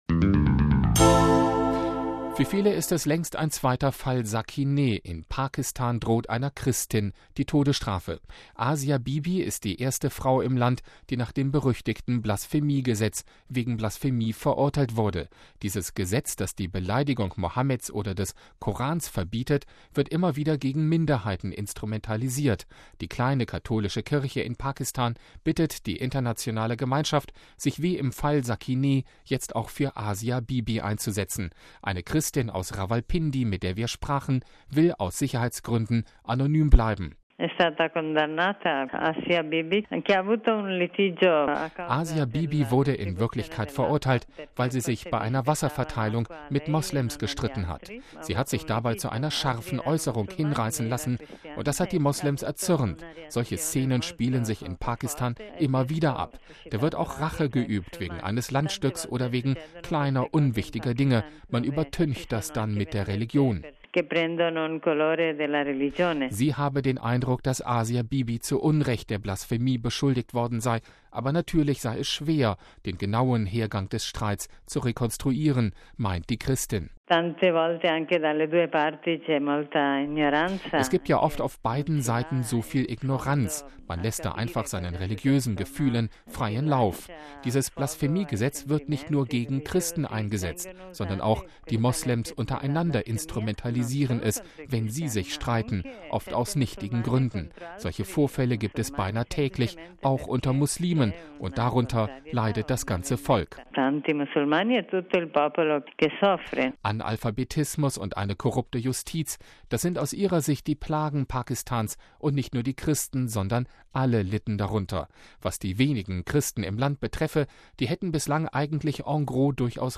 Eine Christin aus Rawalpindi, mit der wir sprachen, will aus Sicherheitsgründen anonym bleiben: